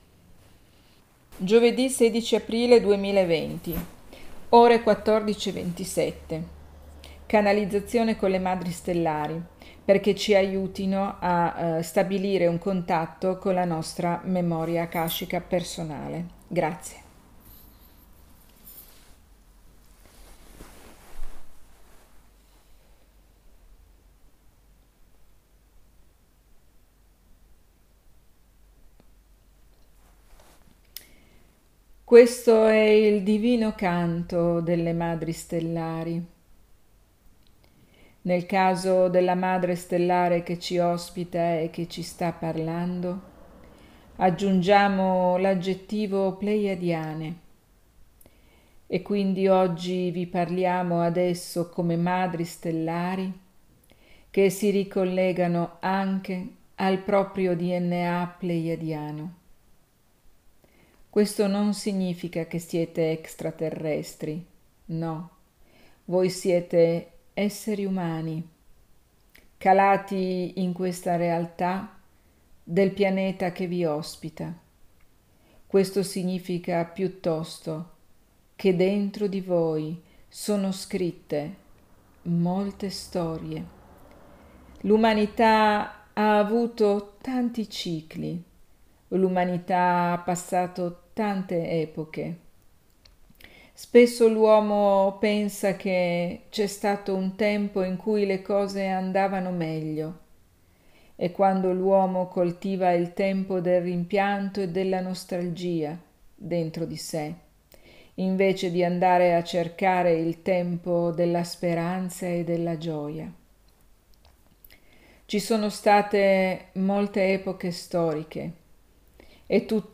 Gioved� 16 aprile 2020 nel webinar del gruppo fb Ponti di Luce
[l'audio della sessione di channeling]